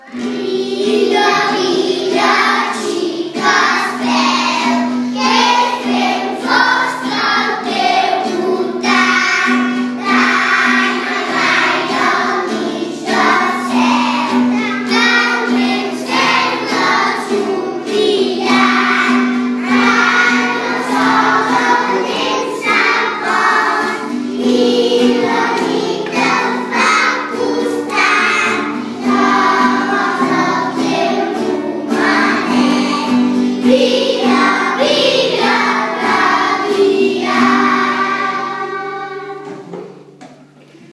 MÚSICA A E. INFANTIL
Els nens i nenes d’educació infantil canten tan i tan bé que els hem gravat perquè els pogueu escoltar des de casa: